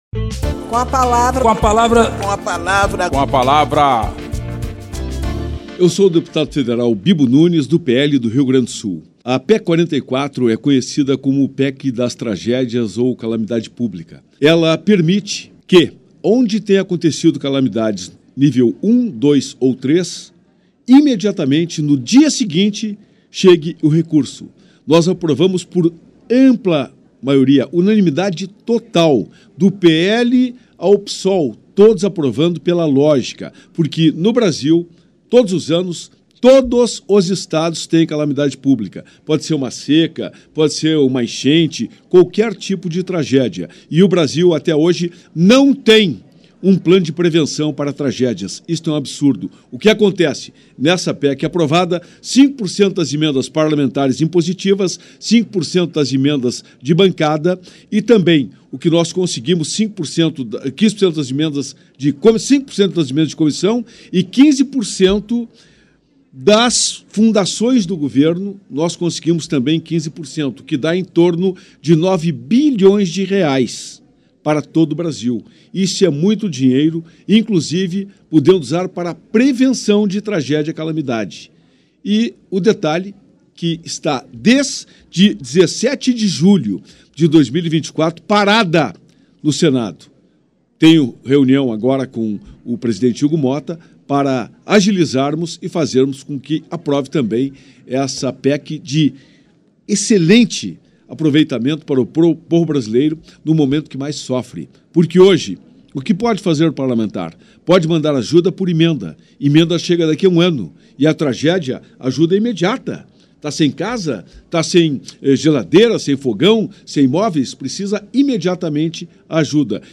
Espaço aberto para que cada parlamentar apresente aos ouvintes suas propostas legislativas